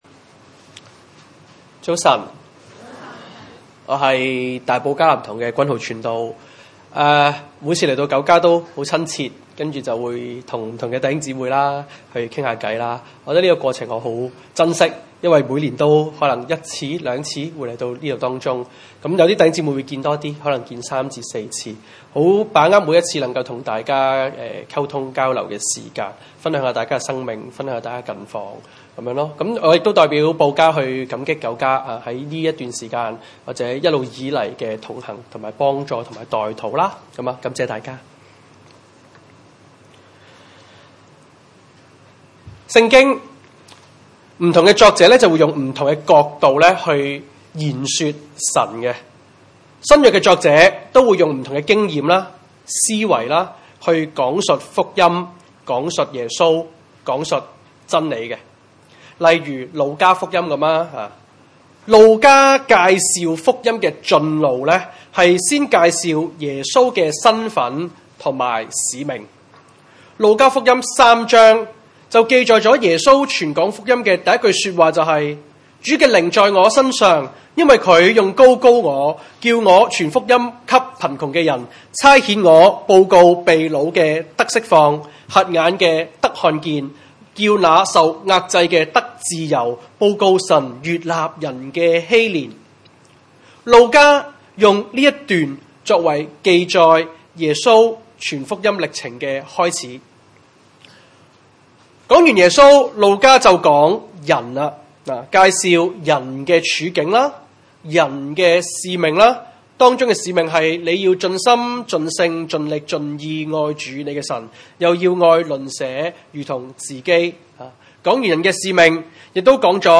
羅馬書14章 崇拜類別: 主日午堂崇拜 1 信心軟弱的，你們要接納，但不要辯論所疑惑的事。